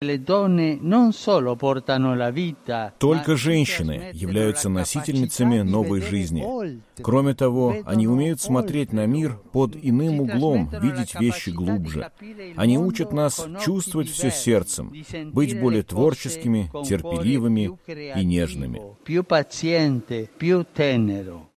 Выступая в воскресенье с проповедью "Ангелус" из окна Апостольского дворца в Ватикане, перед тысячами верующих, понтифик подчеркнул особую роль женщин в современном мире.